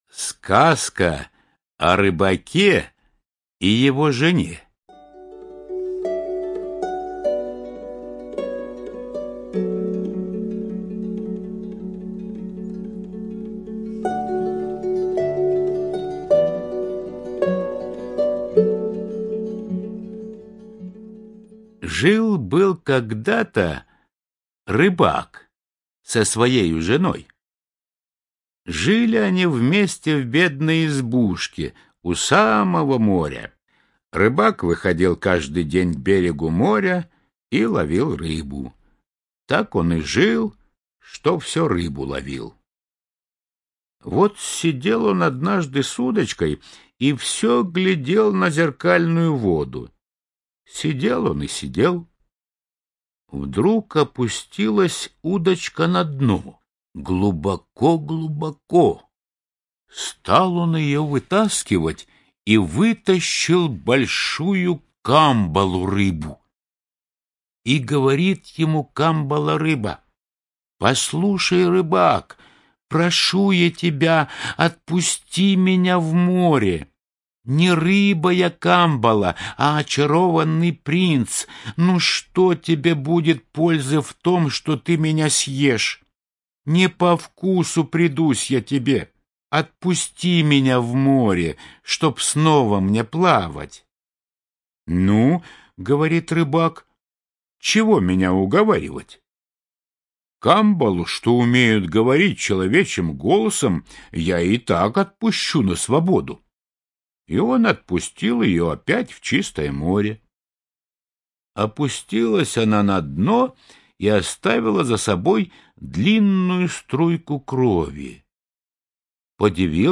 Слушайте онлайн сказку Сказка о рыбаке и его жене - аудиосказка братьев Гримм.